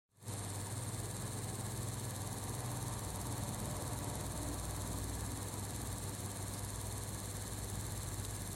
idle.mp3
Работа видеокарты MSI R9 270X Gaming 2G ITX в режиме простоя.